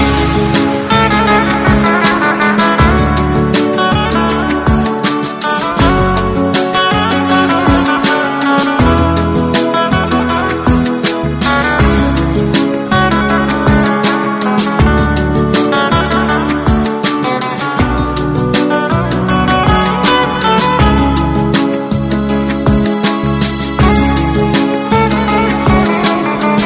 Arabic guitar scale ringtone free download